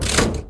Lever1.wav